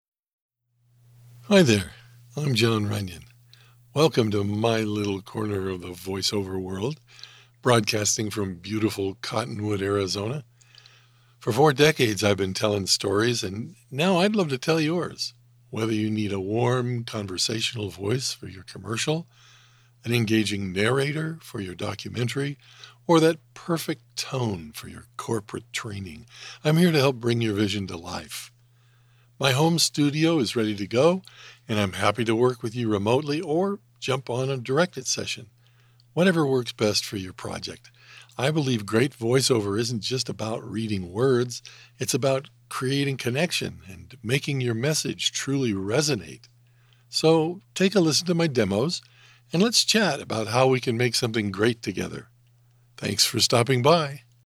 Middle Aged
My delivery is sincere, friendly, believable, and natural—the qualities modern projects demand.